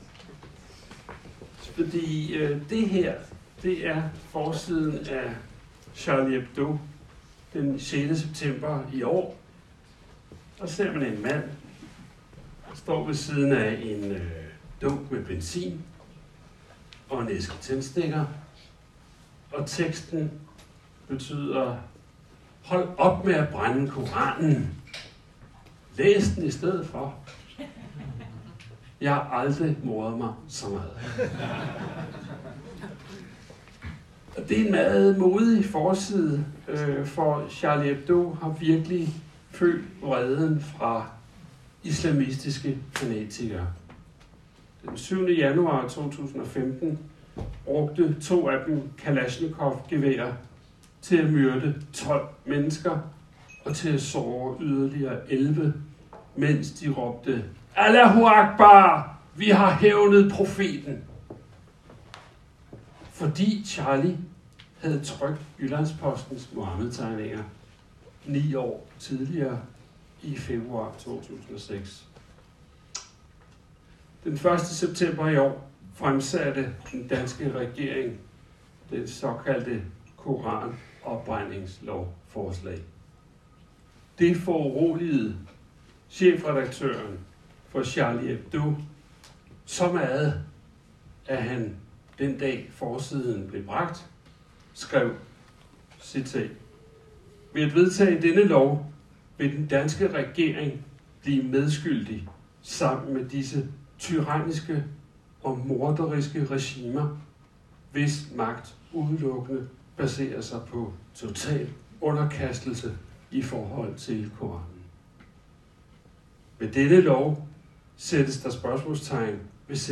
Foredrag
Rahbeks Højskole, Berlingske Media, Pilestræde 34
Tale